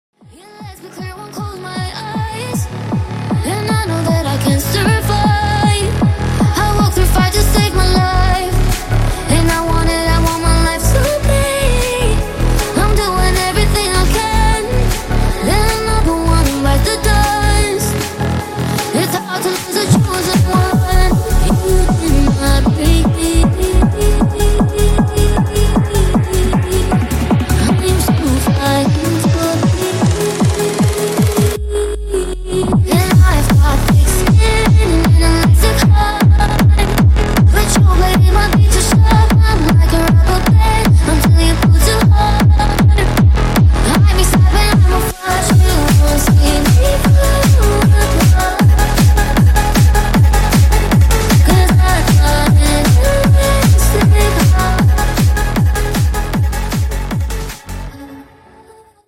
Techno Remix